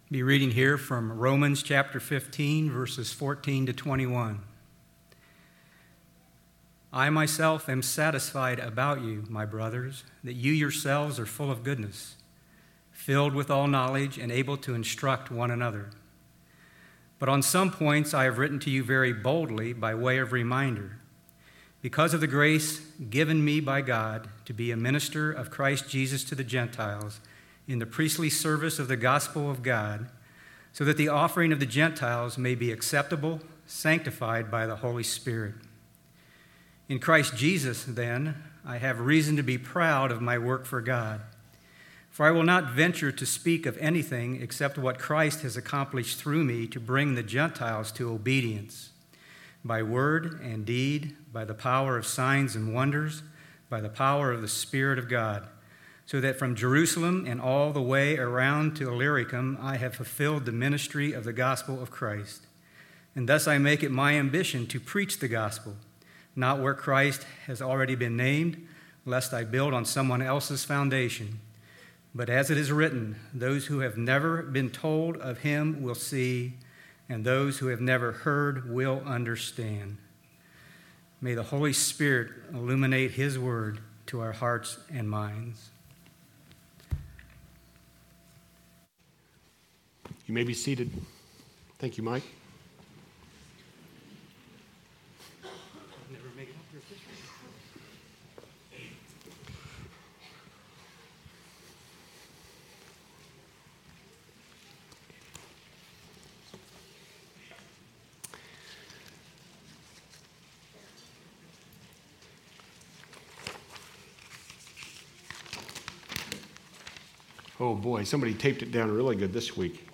Sermons Archive - Delaware Bible Church